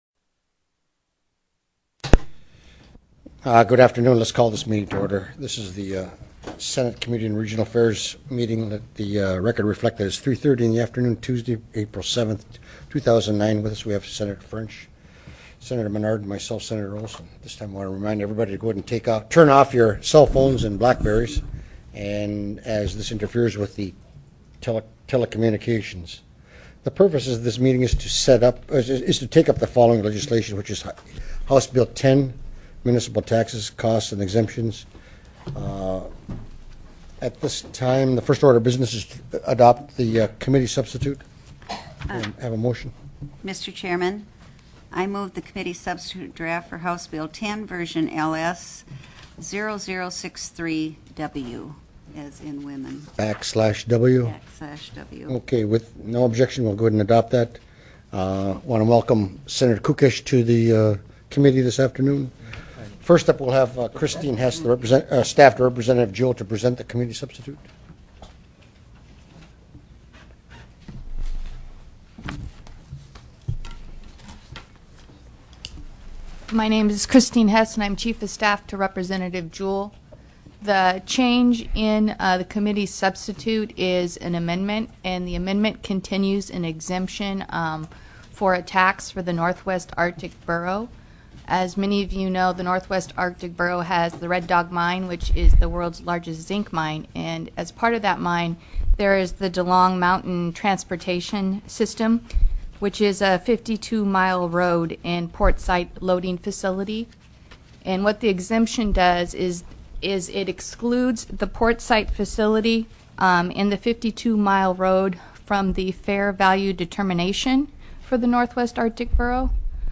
HB 10 MUNICIPAL TAXES: COSTS/EXEMPTIONS TELECONFERENCED